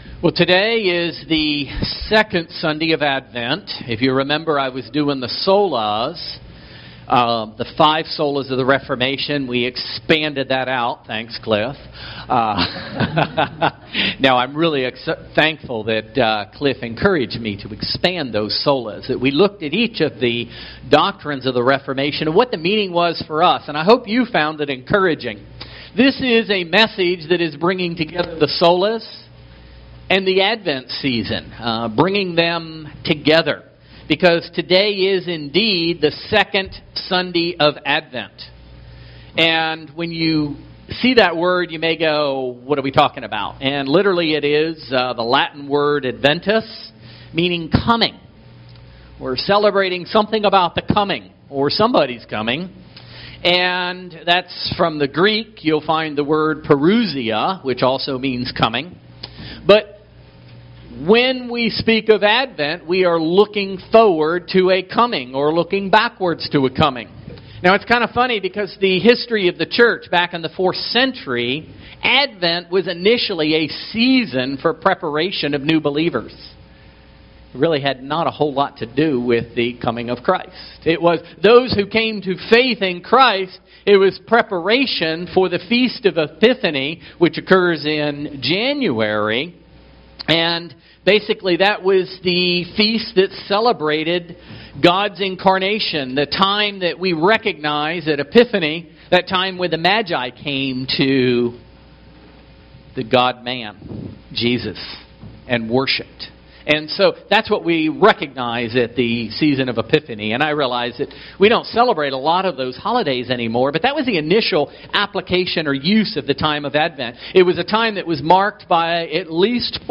Service Type: Special non-Sunday service